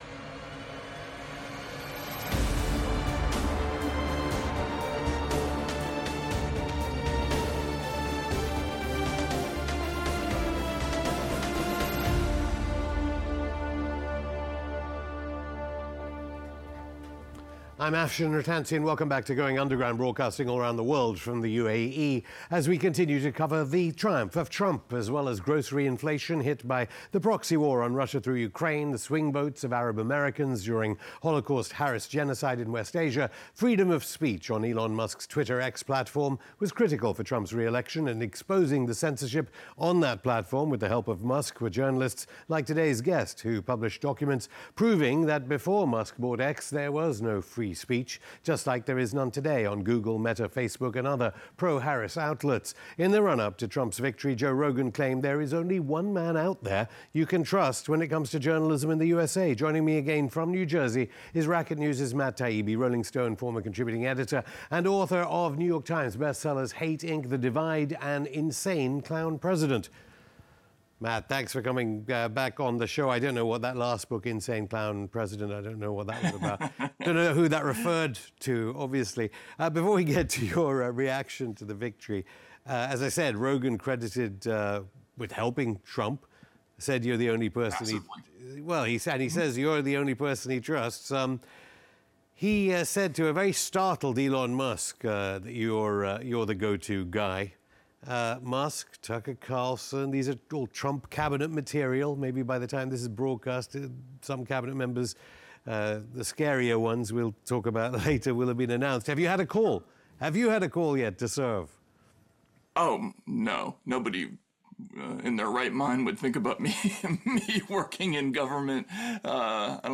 Matt Taibbi on Donald Trump’s Victory: Legacy Media is DEAD, Americans Have DEFIED US Authorities (Afshin Rattansi interviews Matt Taibbi; 11 Nov 2024) | Padverb